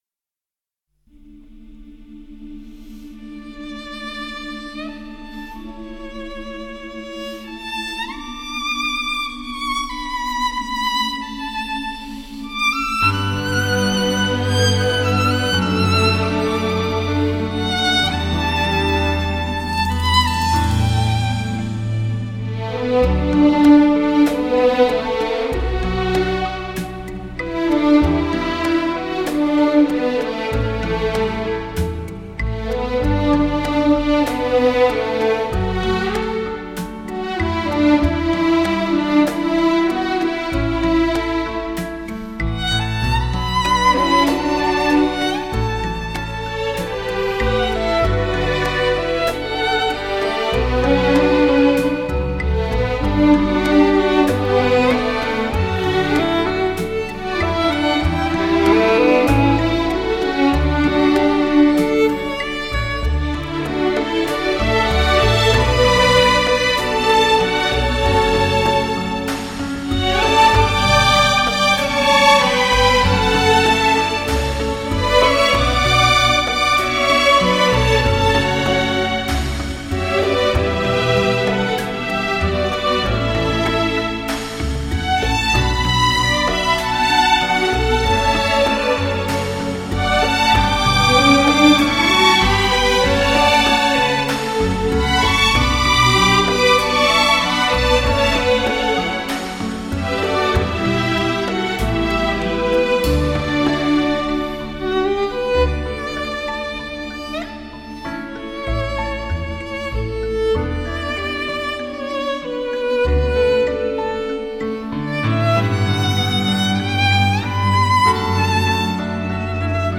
众所周知，小提琴的表现力极为丰富，它的音域宽广，音色优美，歌唱性强，最接近人声，有着非常丰富多样的演奏技巧，感染力极强。